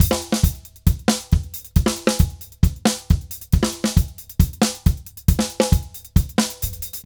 Drums_Merengue 136-1.wav